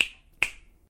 snap1.ogg